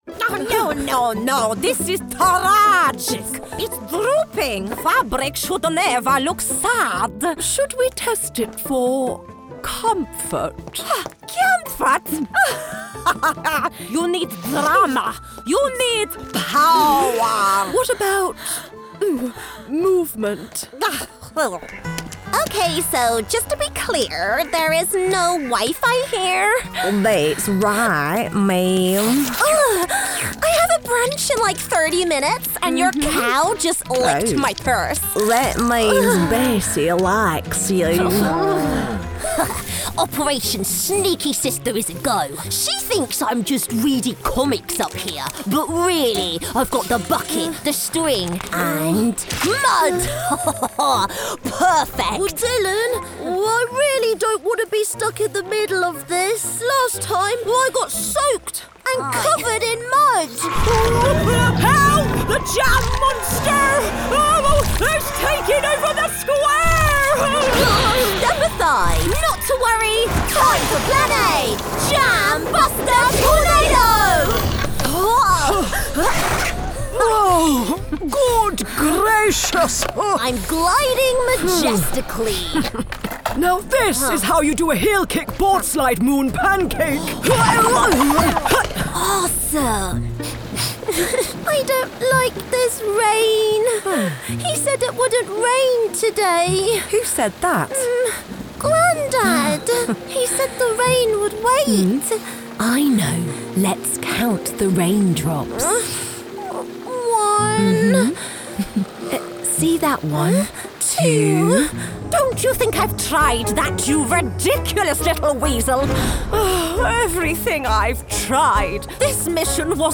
Animation Showreel
Her neutrality paves the way for expert narration skills but her ability to hone in on her regional accents, of which she is so in-tune with, makes her perfect for those Cordial Commercial reads.
Female
Neutral British
Confident
Reassuring
Bright
Friendly
animationreel-cutdown.mp3